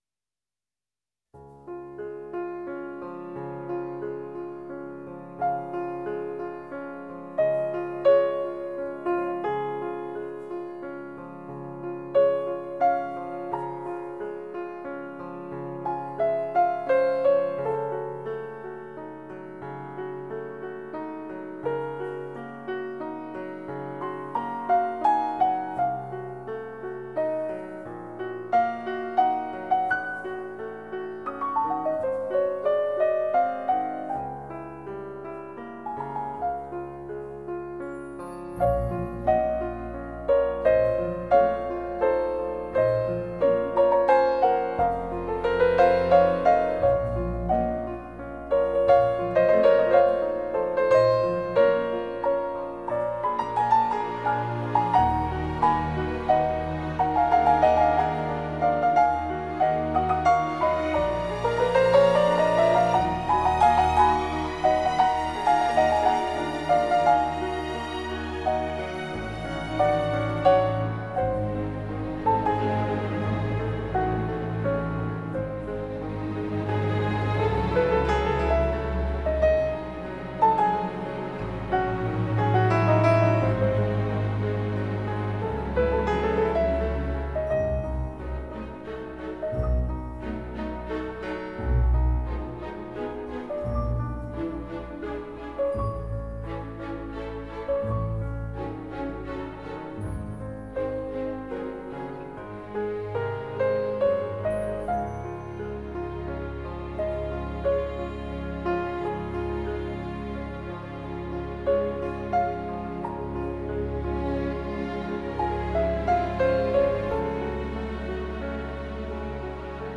2周前 纯音乐 9